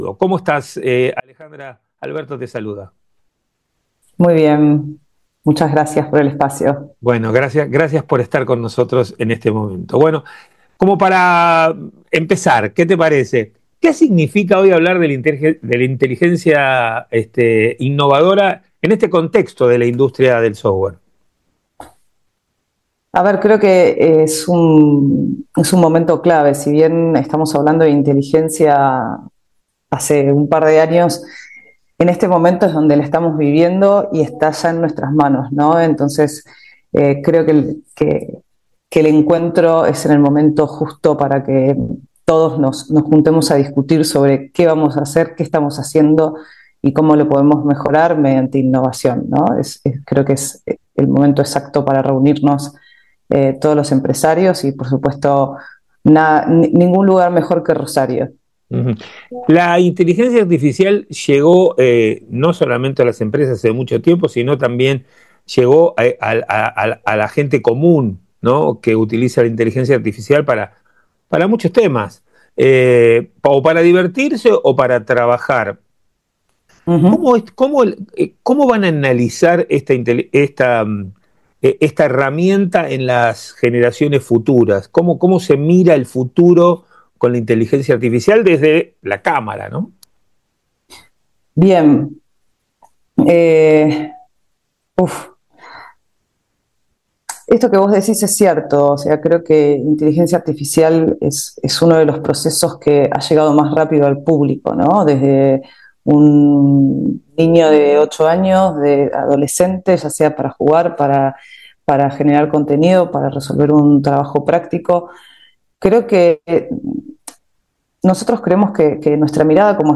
Entrevista a